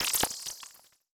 Futuristic Sounds (25).wav